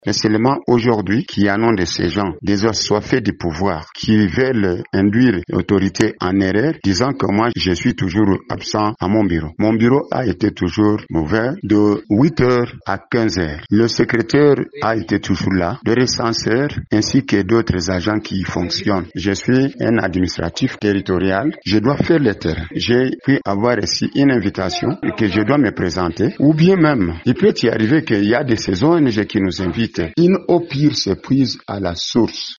Ils l’ont exprimé dimanche dernier au cours d’une émission publique organisée sur place à Mumosho Centre par Radio Maendeleo.